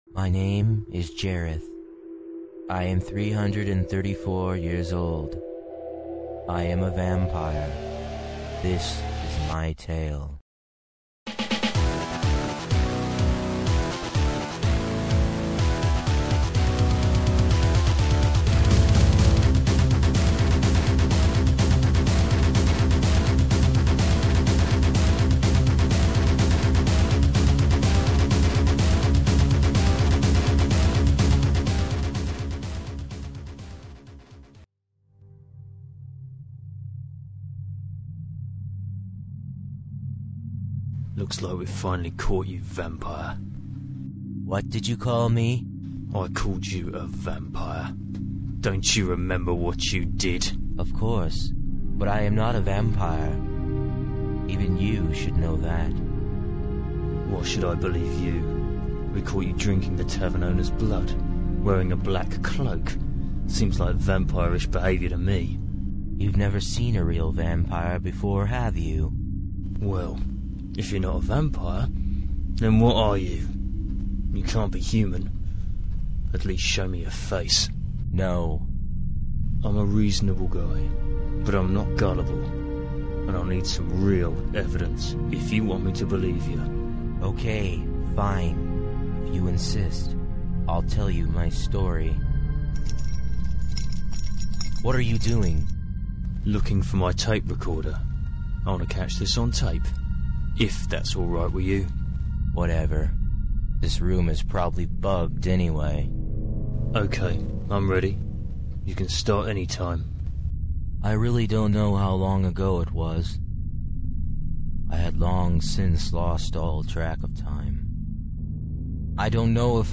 Involvment: Acting (Jailer)
One of the first parts I snagged after my few years out of production was as a bit part Jailer in City Nocturne.